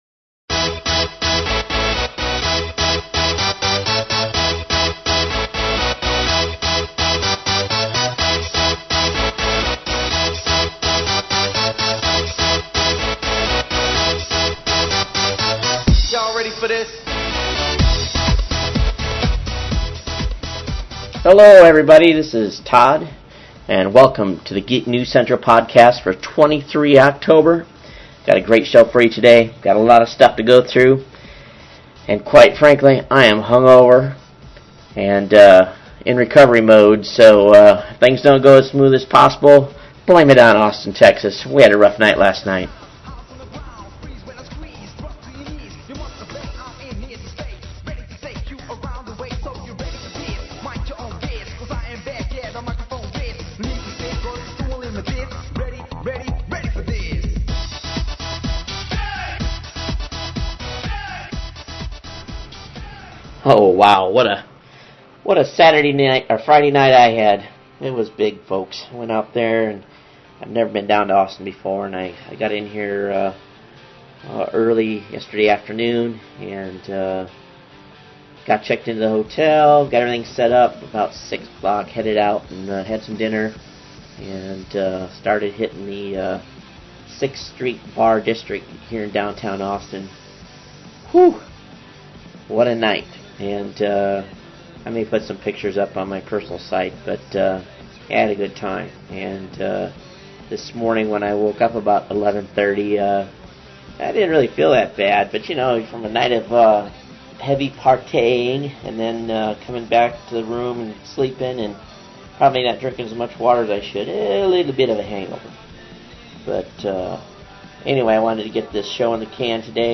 Today's podcast comes from Austin Texas, The show today includes a decent offering of different topics and we cover a wide variety of sites.
This Podcast Features background Music